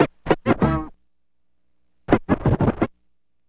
HORN SCRA07R.wav